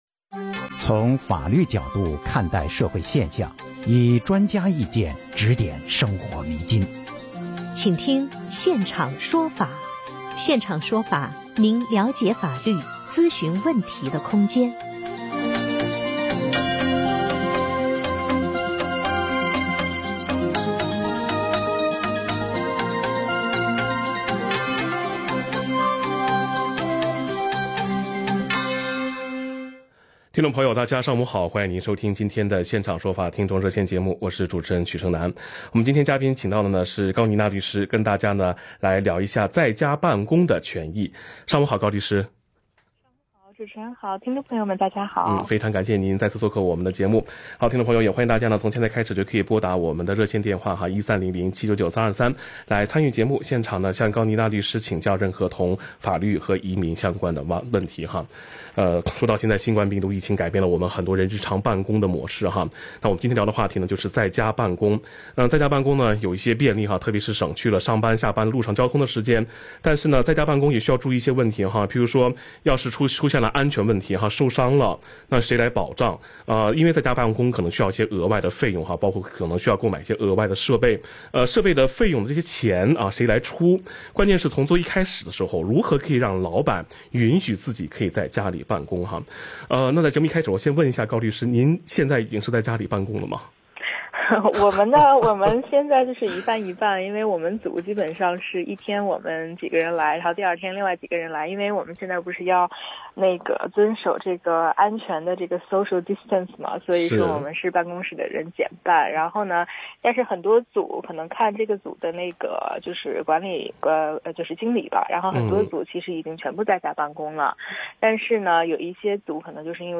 legal_talkback_your_rights_of_working_from_home.mp3